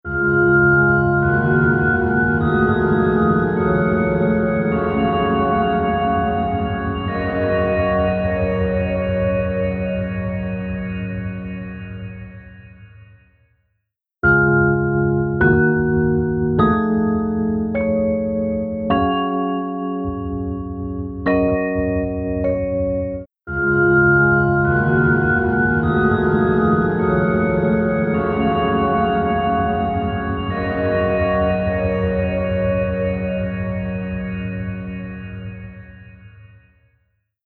ピッチシフティングを融合した、幻想的なリバーブ
ShimmerVerb | Piano | Preset: Root Pin
ShimmerVerb-Eventide-Keys-Root-Pin.mp3